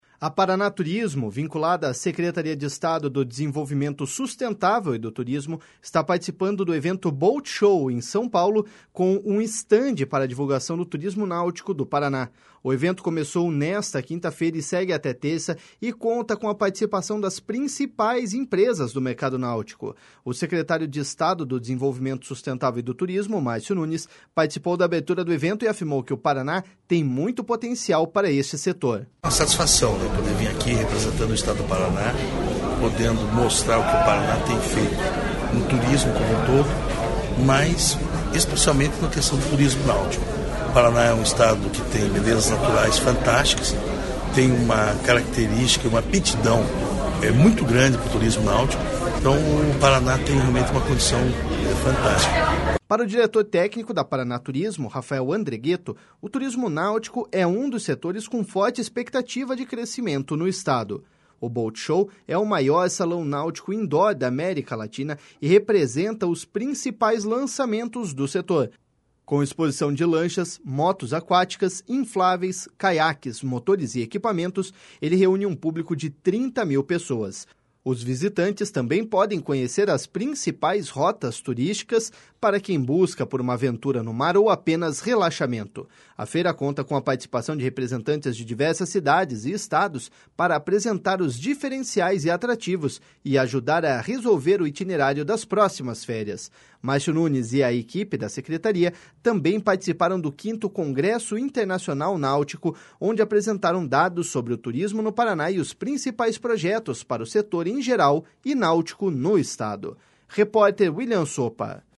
O secretário do Desenvolvimento Sustentável e do Turismo, Márcio Nunes, participou da abertura do evento e afirmou que o Paraná tem muito potencial para este setor.// SONORA MARCIO NUNES.//